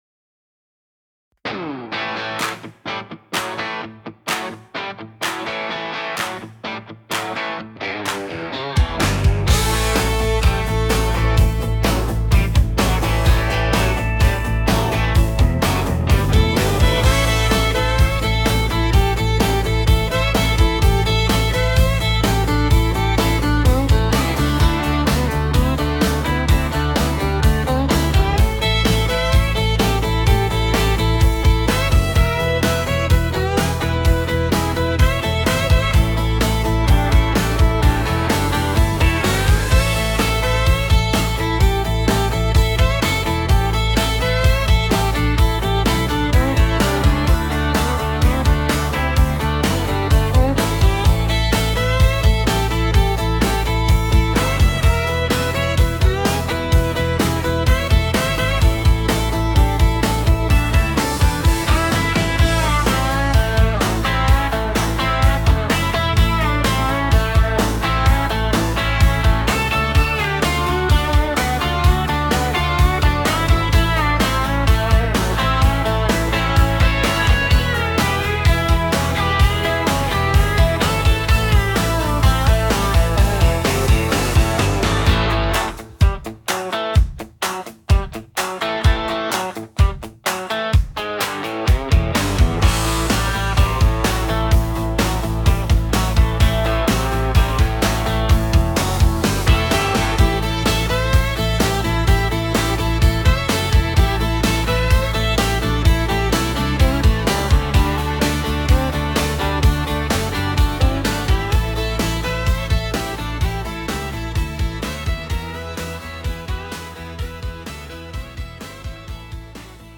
Square Dance Music for Callers and Dancers
(Patter)